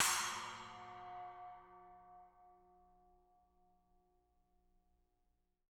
R_B Splash B 02 - Room.wav